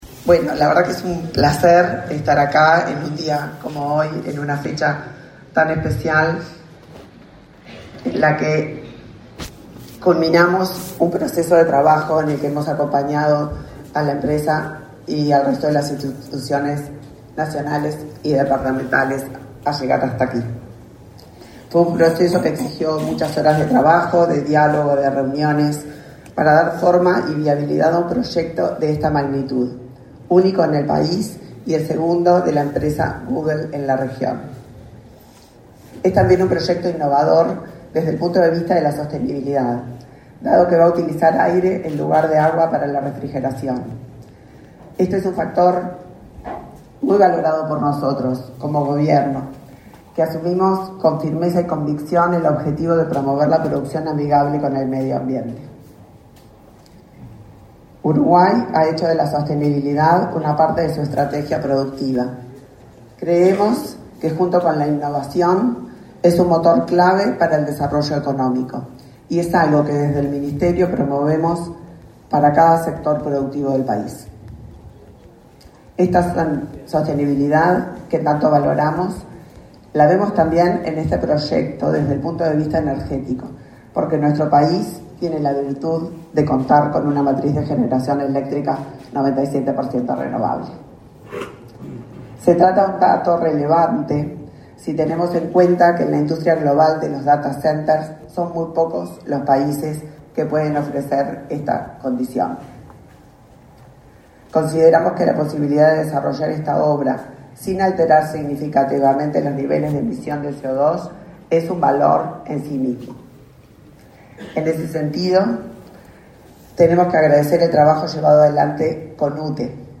Disertaron en el evento la ministra de Industria, Energía y Minería, Elisa Facio, y el ministro de Relaciones Exteriores, Omar Paganini.